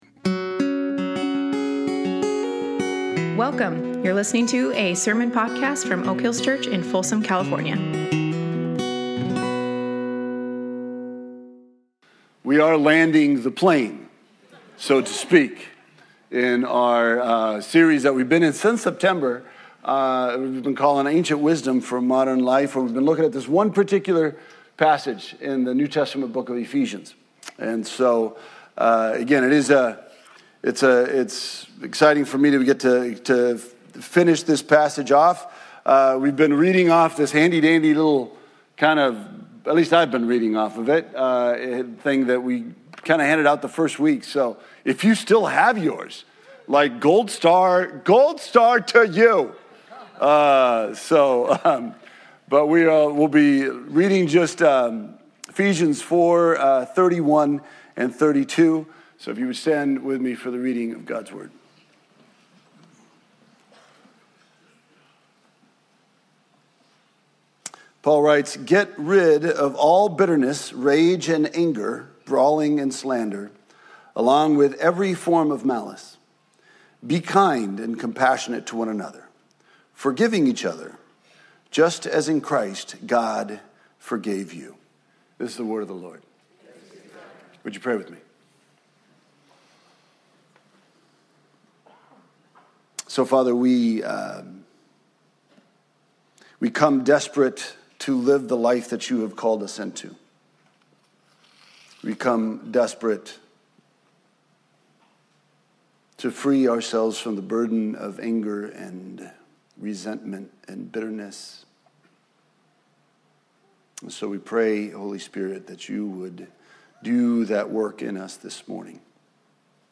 Ephesians 4:23b Service Type: Sunday Morning The step to take here is not to try to forget.